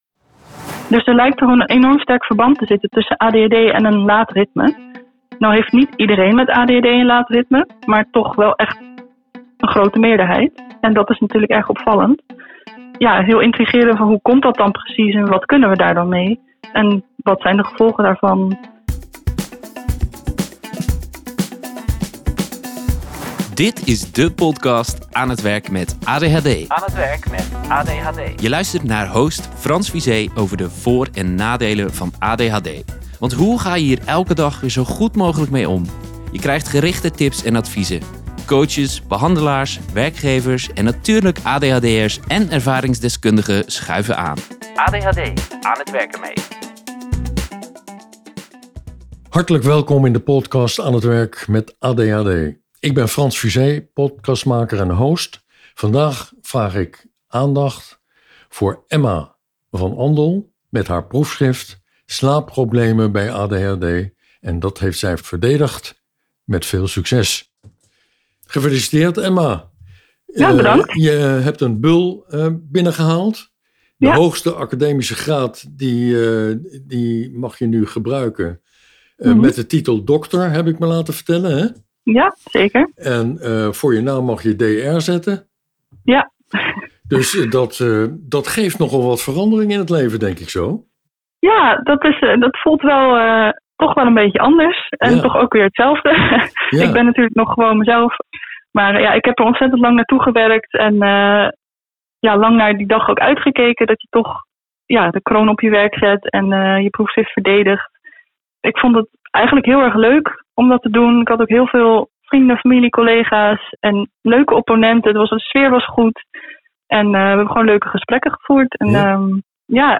luister je mee met de openhartige gesprekken